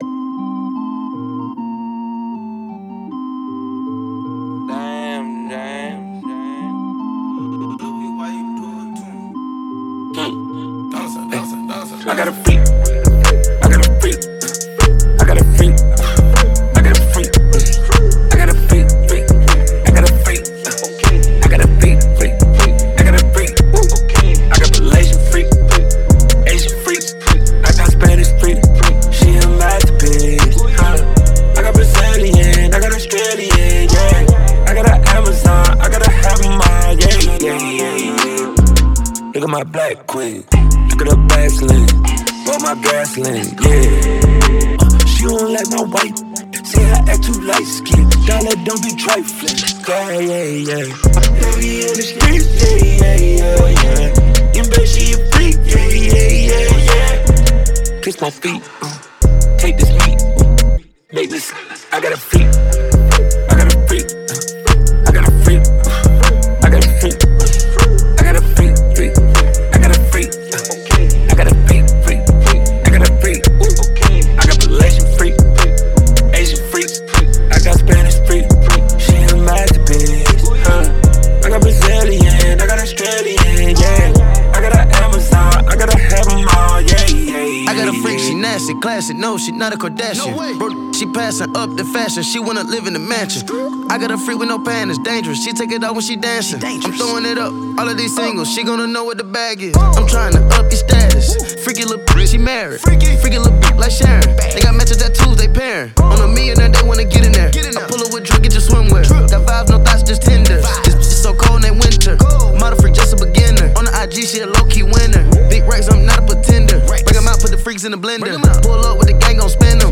Rapper and R&B singer-songwriter